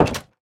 Minecraft Version Minecraft Version latest Latest Release | Latest Snapshot latest / assets / minecraft / sounds / block / wooden_door / close3.ogg Compare With Compare With Latest Release | Latest Snapshot